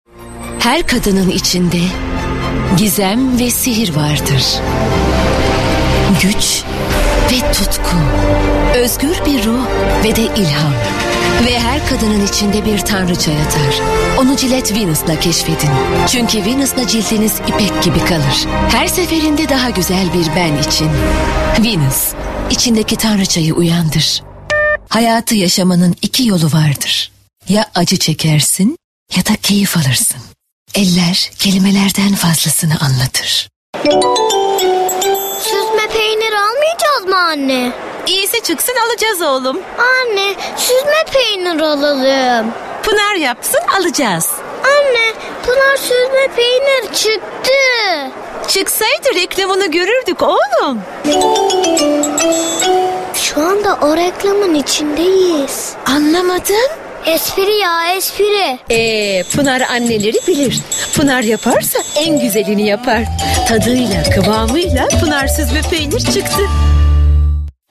Reklam Filmi Seslendirme